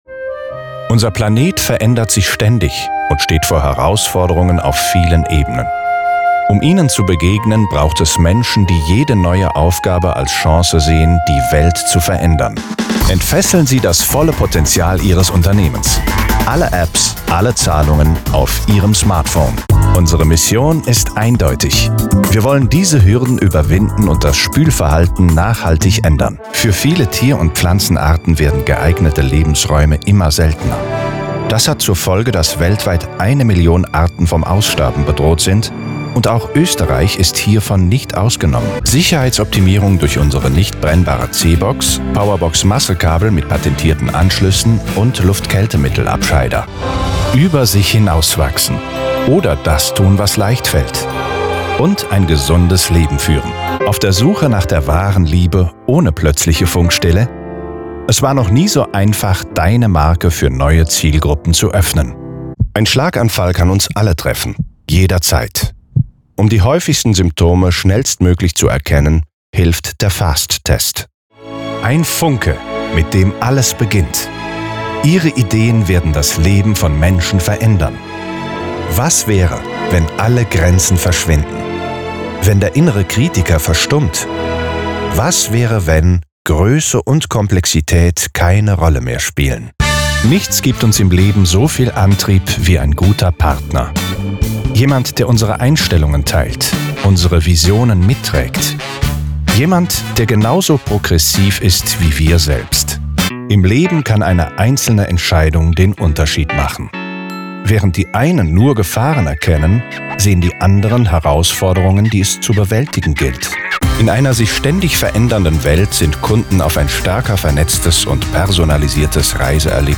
markant, dunkel, sonor, souverän, sehr variabel, plakativ
Mittel plus (35-65)
Trailer Werbung
Commercial (Werbung)